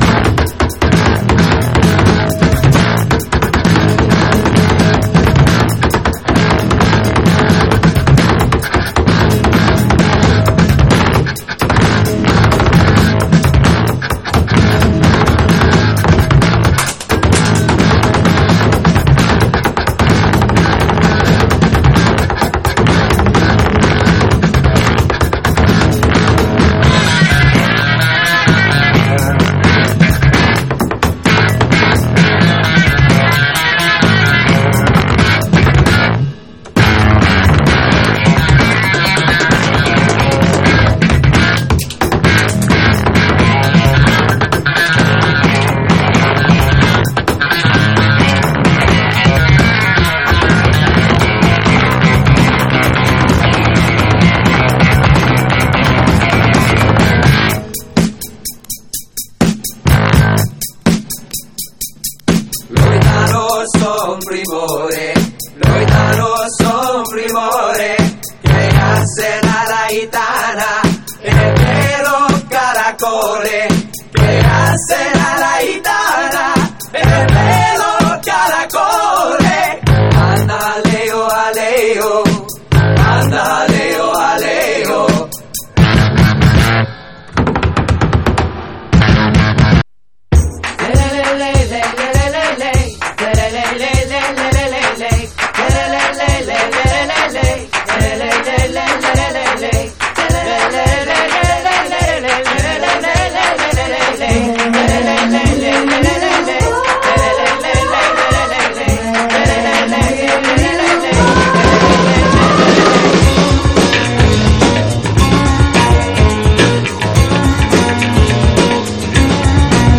ROCK / 90''S～ / INDIE ROCK (JPN)